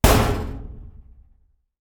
48d440e14c Divergent / mods / Soundscape Overhaul / gamedata / sounds / ambient / soundscape / underground / under_5.ogg 46 KiB (Stored with Git LFS) Raw History